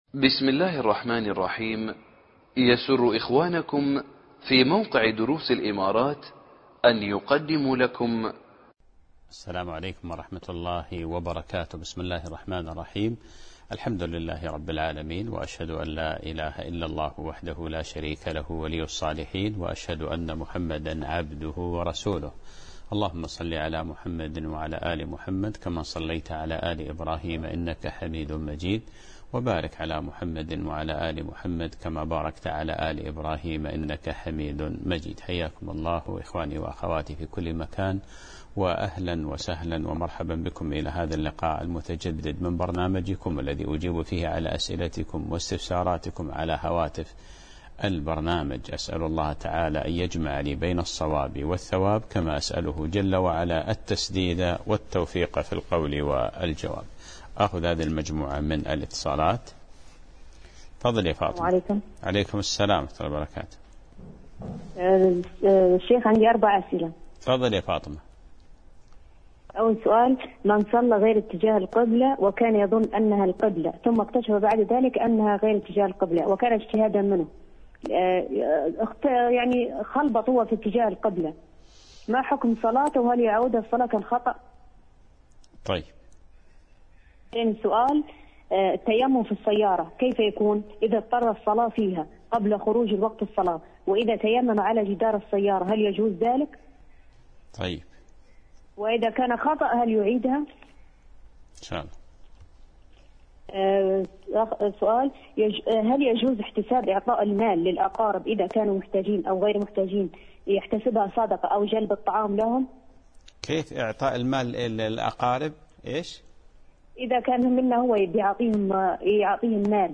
الدرس 7